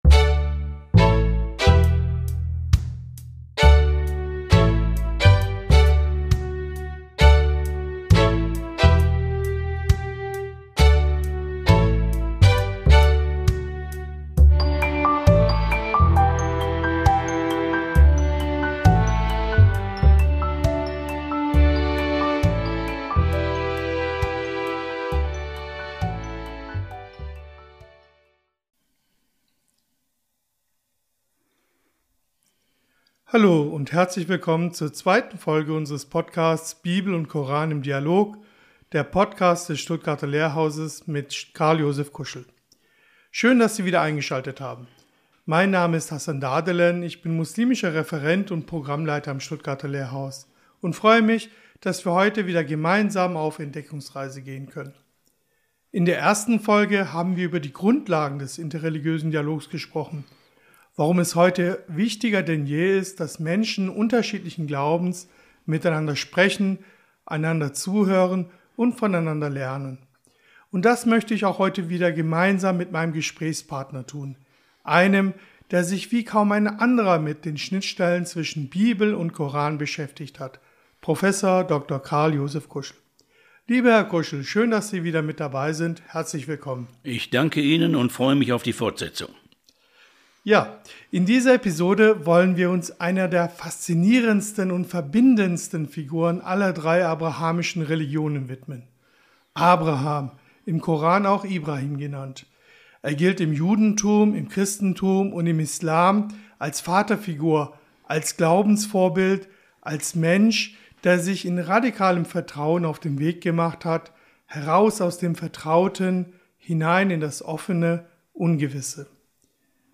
Ein persönliches, theologisches und gesellschaftlich relevantes Gespräch über Vertrauen, religiöse Vielfalt und die gemeinsame Verantwortung für eine friedliche Zukunft.